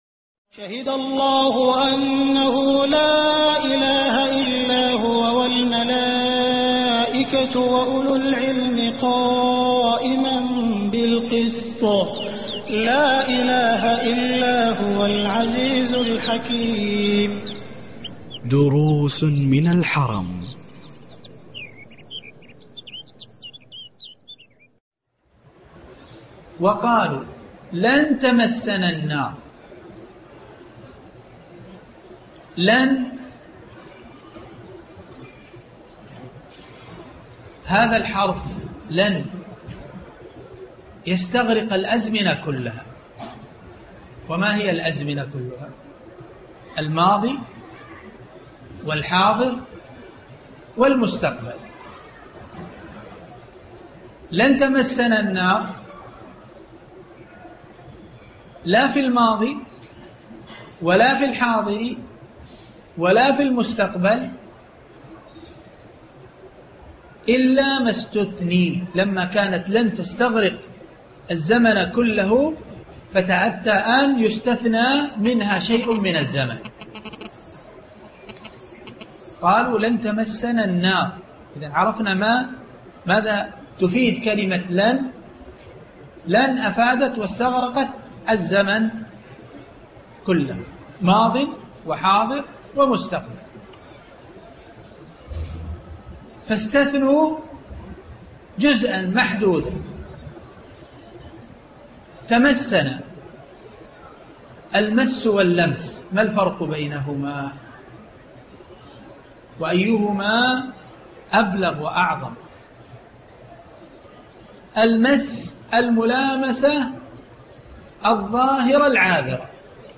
الدرس 22 وقفات في سورة البقرة ج2 (دروس من الحرم)